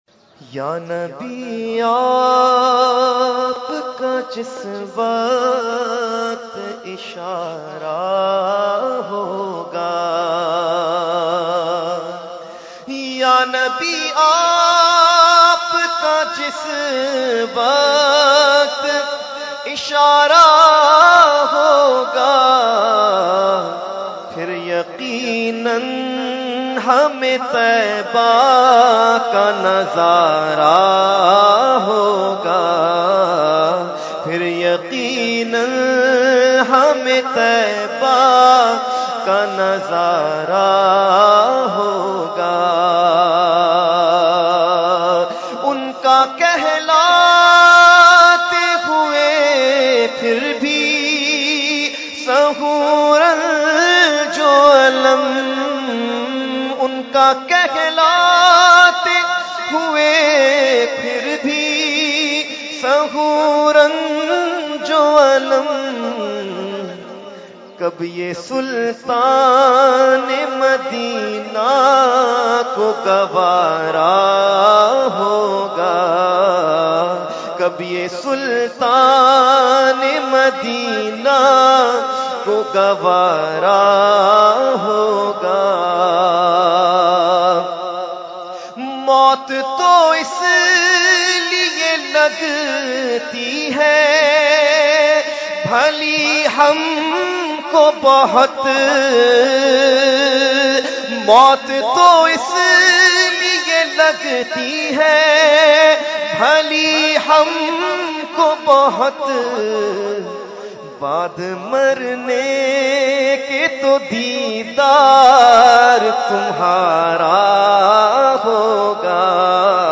Category : Naat | Language : UrduEvent : Urs Qutbe Rabbani 2016